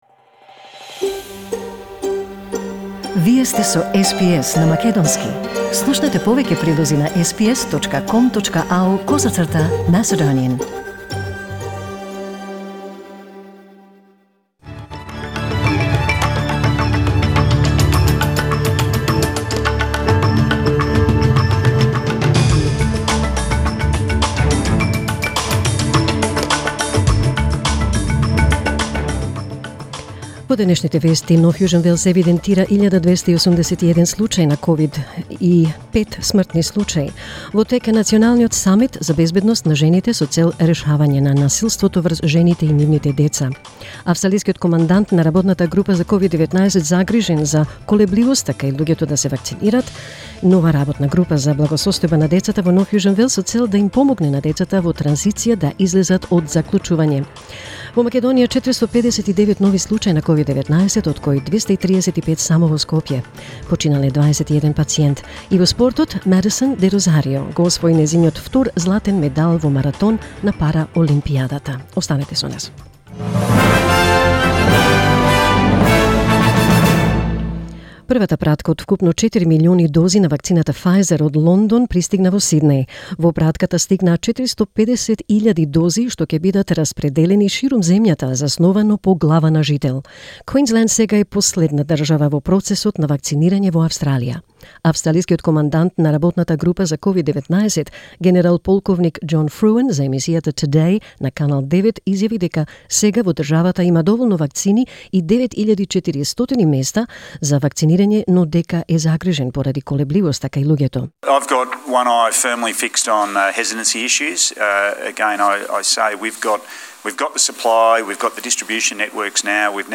SBS News in Macedonian 6 September 2021